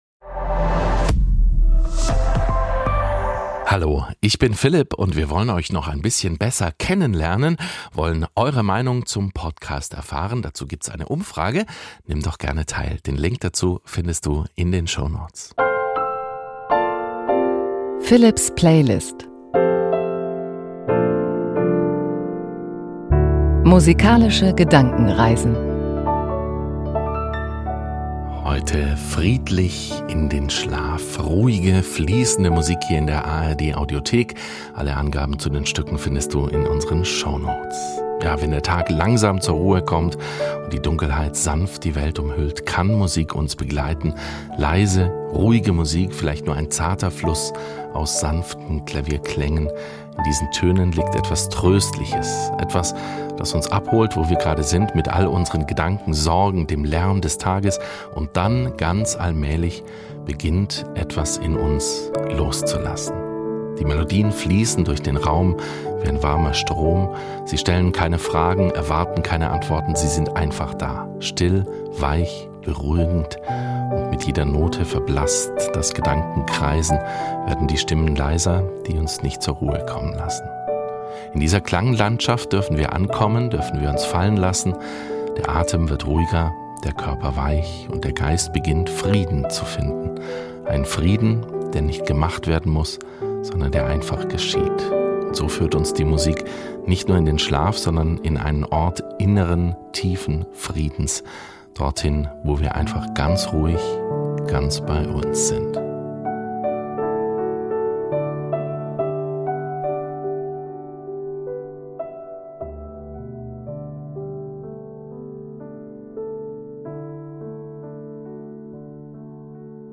Von Pop bis Klassik – die Musik ist für ihn ein Anlass, sich gemeinsam mit seinen Hörerinnen und Hörern auf fantasievolle Gedankenreisen zu begeben.
Zu den einzelnen Stücken jeder Folge improvisiert er am Klavier.